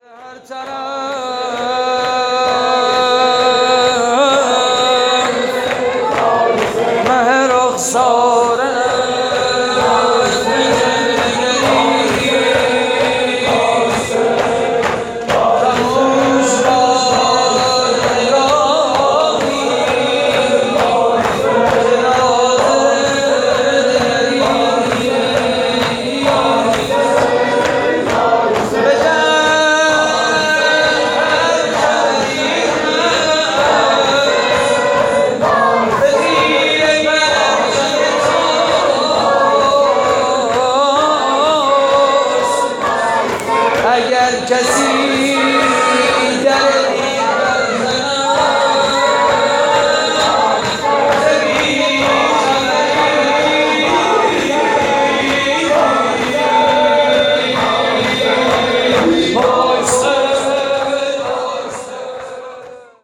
مراسم روضه ماهانه شط شراب (حرکت کاروان از مدینه) / مسجد لولاگر؛ 17 مرداد 97
شور - زمزمه: ز هر طرف مه رخساره‌ات به جلوه‌گری‌ست